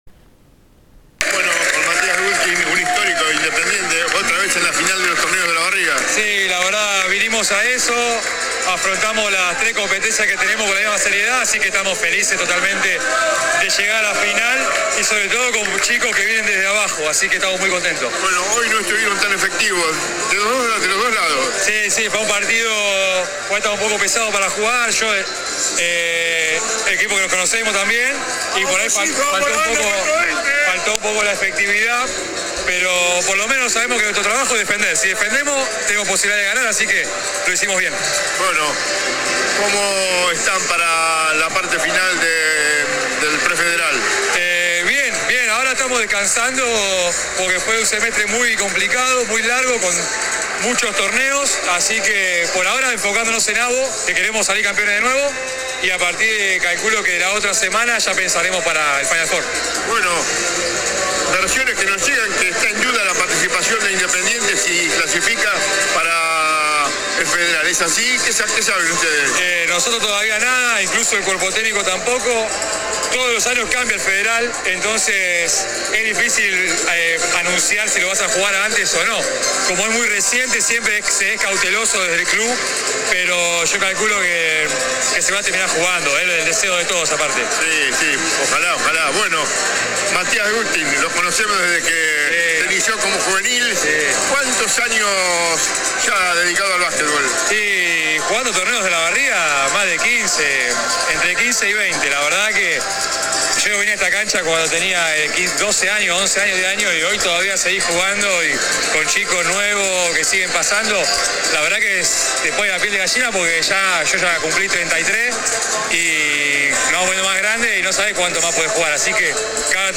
Terminado el partido dialogó con «Emblema Deportivo».
AUDIO DE LA ENTREVISTA